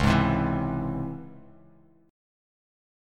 C#mM7 chord